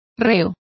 Complete with pronunciation of the translation of culprits.